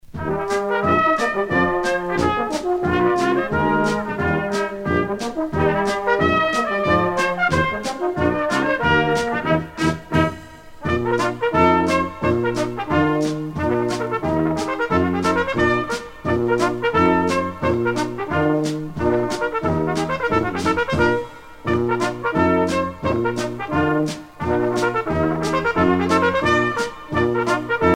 danse : scottich trois pas
Pièce musicale éditée